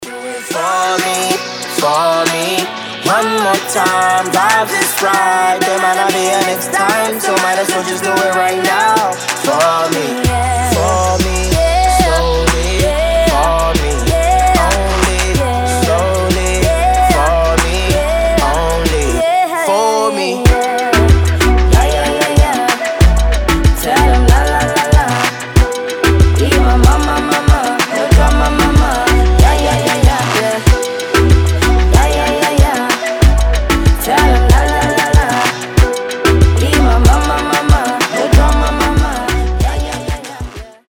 • Качество: 320, Stereo
красивые
заводные
dance
дуэт
Reggaeton
мужской и женский вокал
dance hall